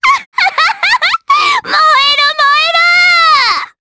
Pansirone-Vox_Skill3_jp.wav